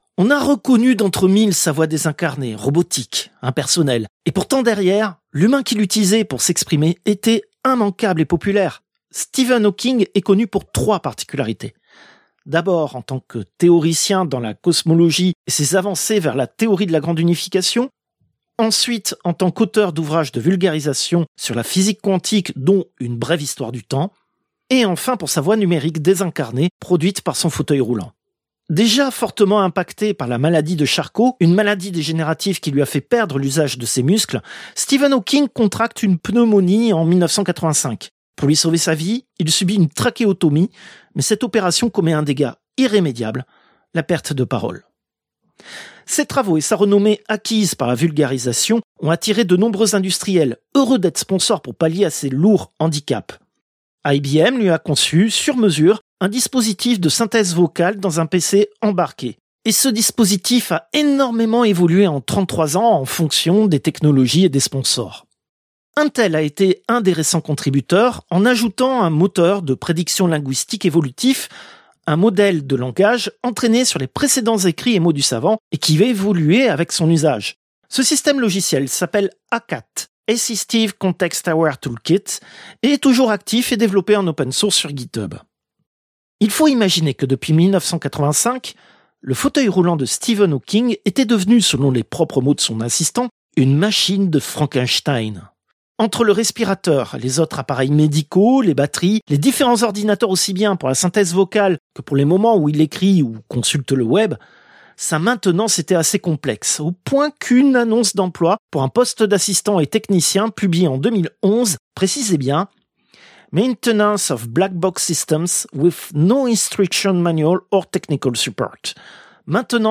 Extrait de l'émission CPU release Ex0225 : Synthèse vocale, seconde partie.